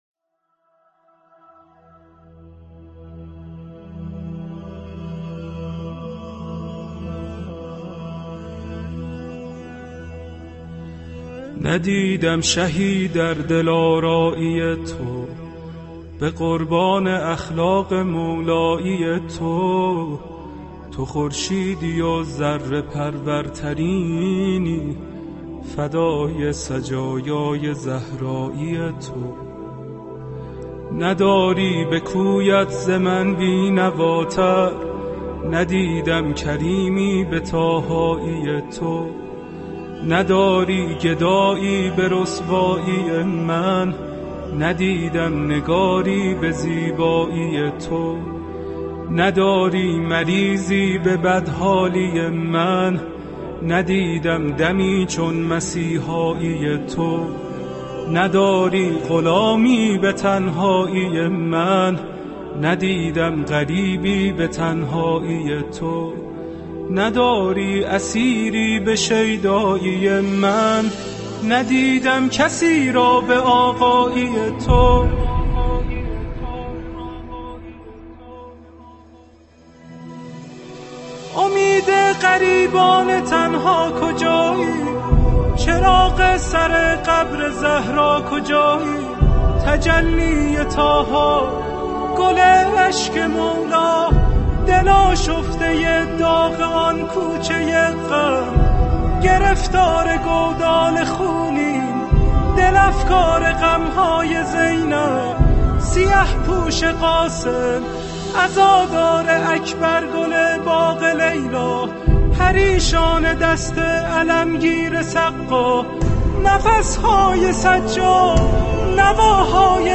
دکلمه-امام-زمان2.mp3